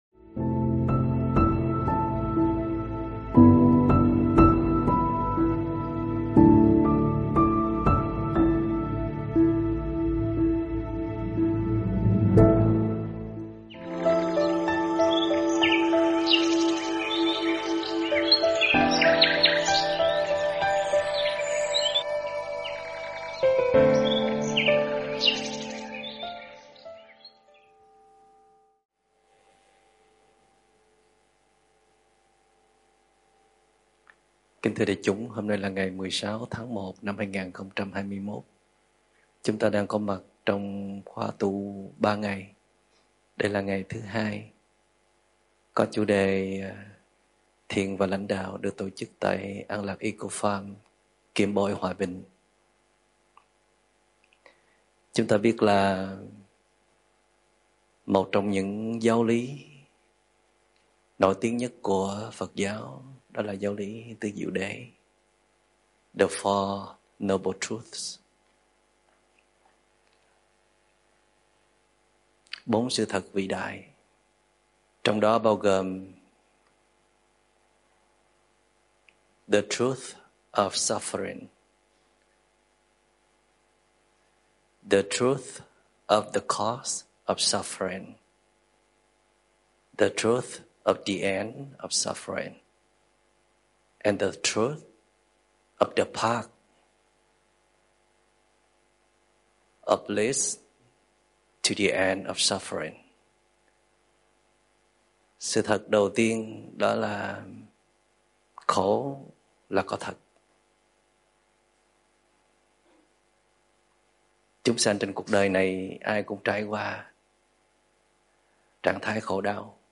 Thiền và Lãnh đạo Thuyết pháp